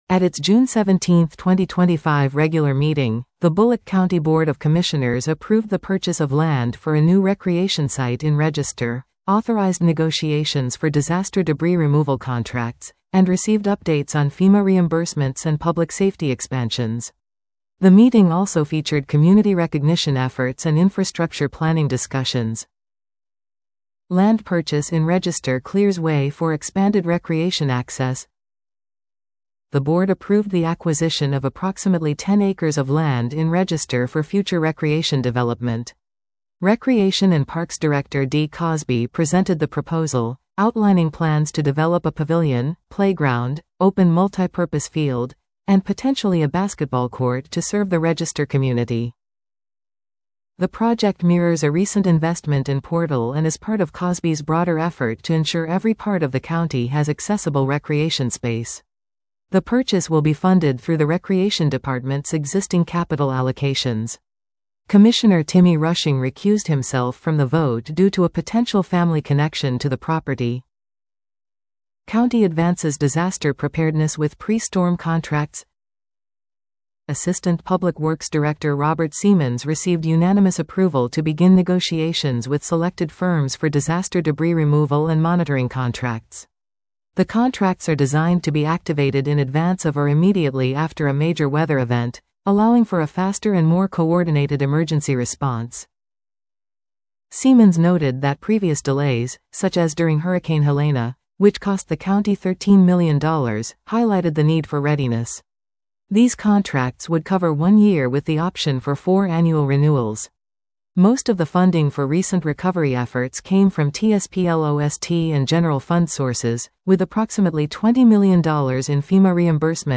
Board of Commissioners meeting 6/17/25